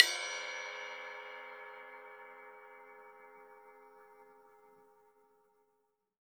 Index of /90_sSampleCDs/USB Soundscan vol.10 - Drums Acoustic [AKAI] 1CD/Partition D/06-ALL RIDE